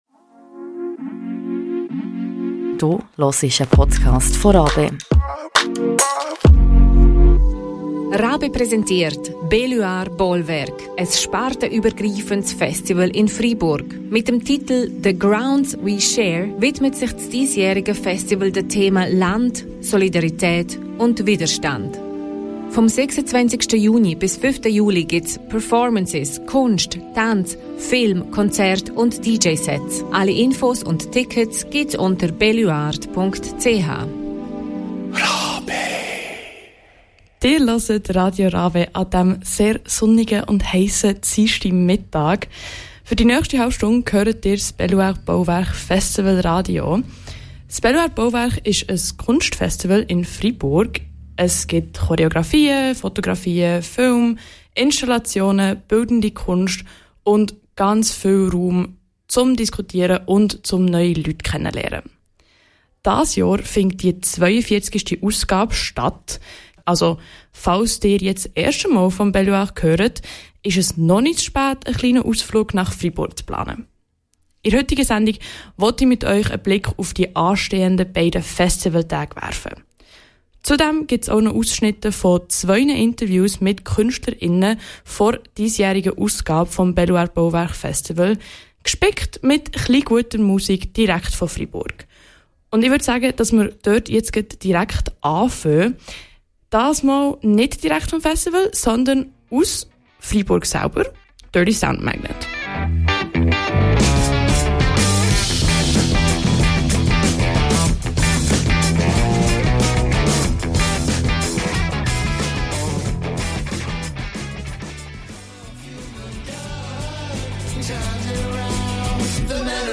Zwei Künstlerinnen geben exklusive Einblicke: